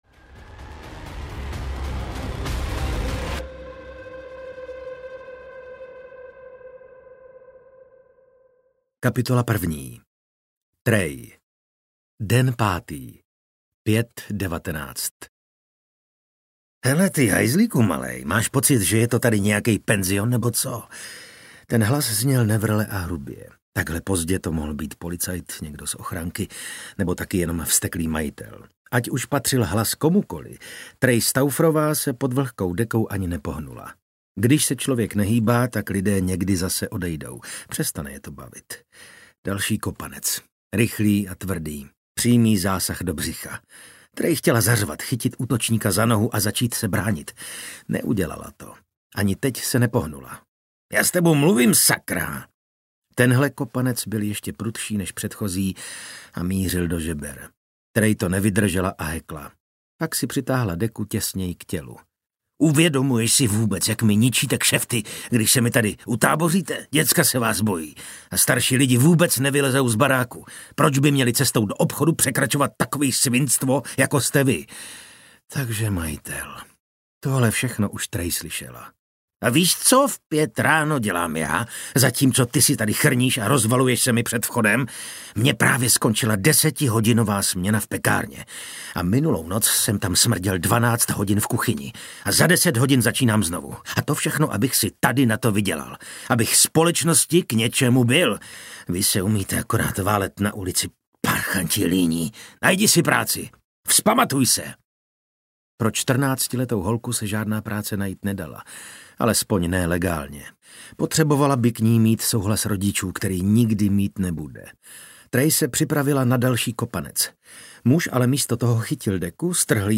Šesté dítě audiokniha
Ukázka z knihy